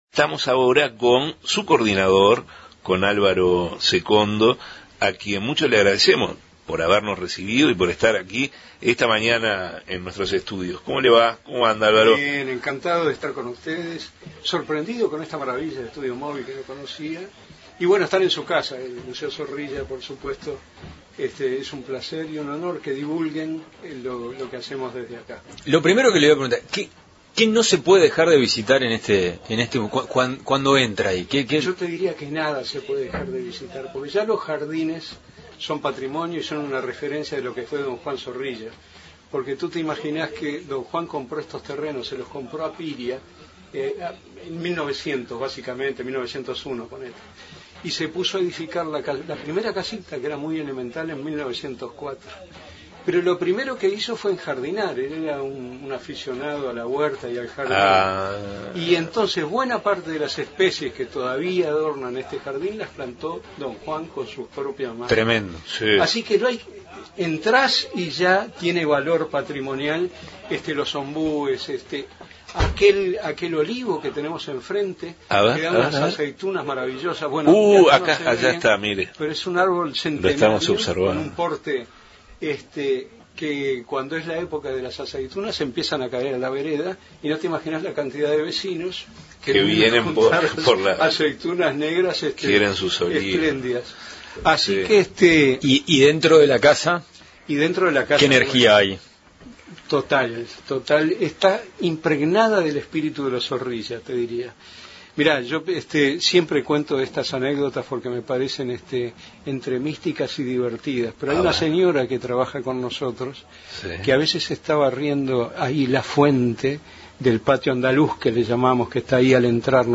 En los estudios móviles de los Medios Públicos, emplazado frente al Museo Zorrilla